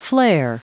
Prononciation du mot flare en anglais (fichier audio)
Prononciation du mot : flare